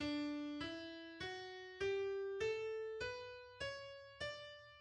d - e - fis - g - a - b - cis - d
De toonladder D-majeur